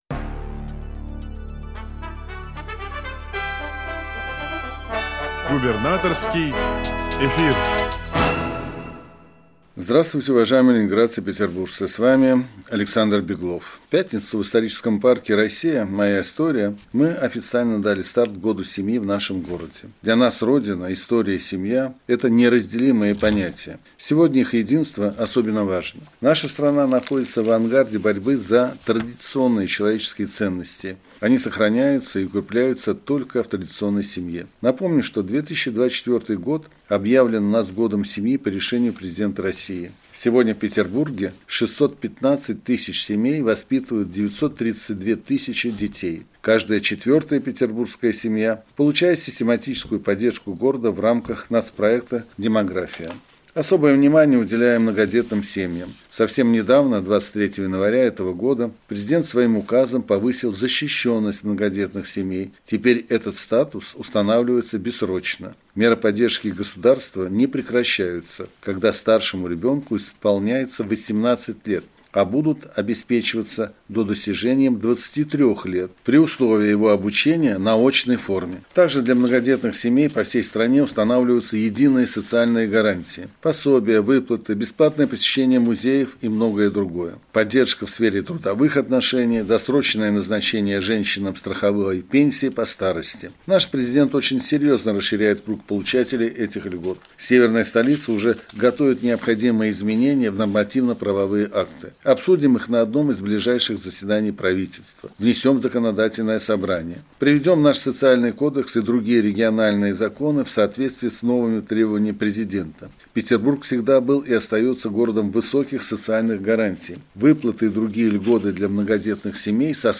Радиообращение – 5 февраля 2024 года